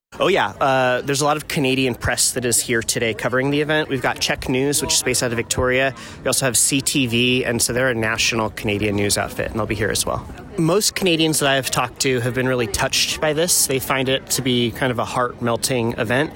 Port Angeles – Despite the cold, the mood was warm and festive Thursday afternoon at the Coho Ferry terminal where hundreds of people showed up with signs and banners welcoming the ferry and its Canadian passengers back to Port Angeles after the boat’s annual winter break.